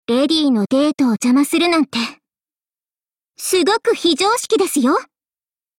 文件:Cv-20227 warcry 5.mp3 - 萌娘共享